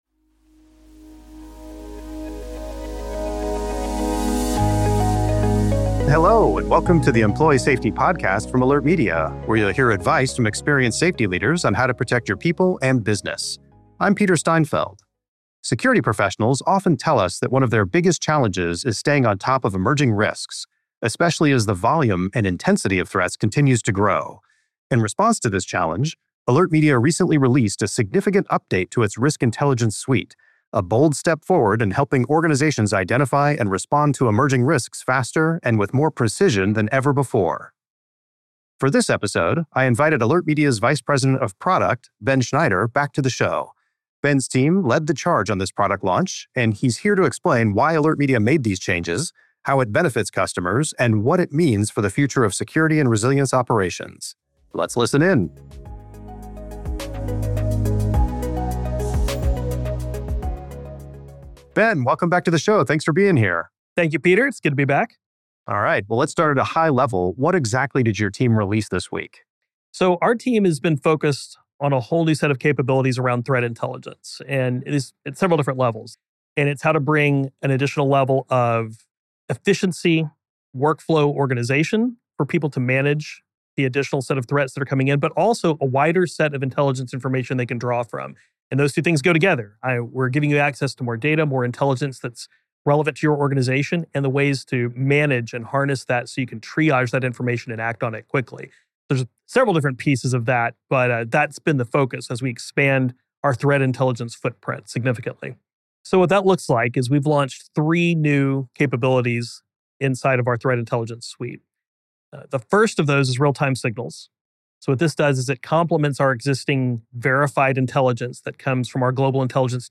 You can find this interview and many more by following The Employee Safety Podcast on Spotify or Apple Podcasts .